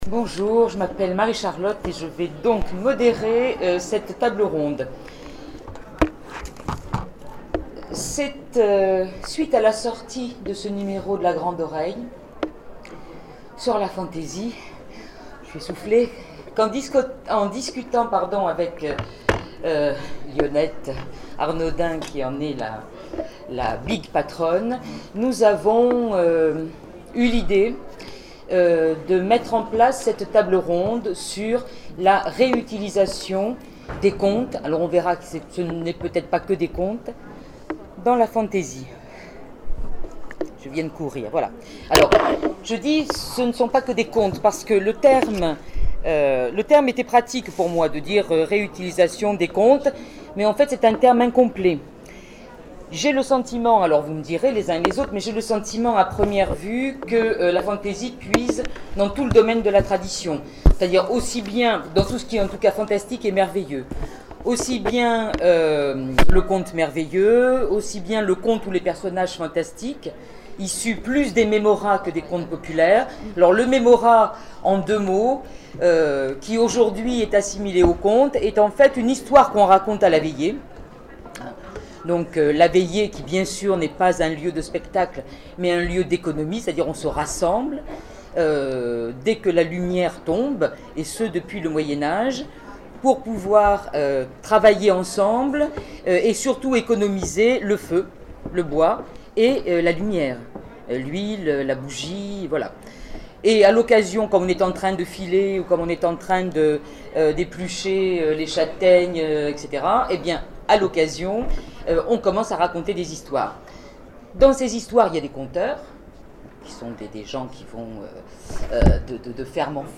Zone Franche 2012 : Conférence La réutilisation des contes et légendes dans la fantasy